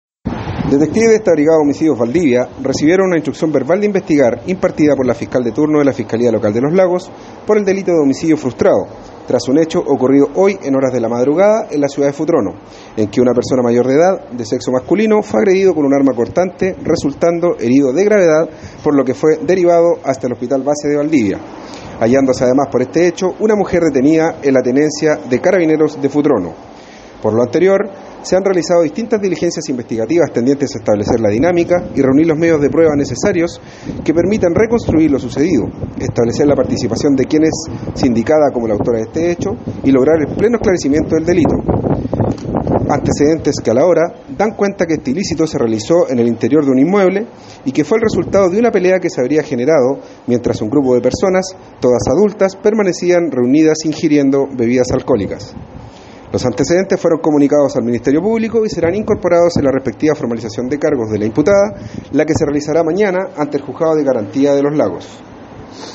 Cuña